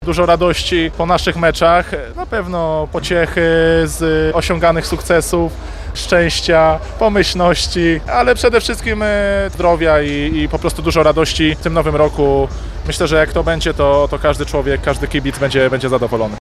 Takie też kibicom siatkówki za pośrednictwem Radia Lublin przekazuje kapitan Bogdanki LUK Lublin Marcin Komenda.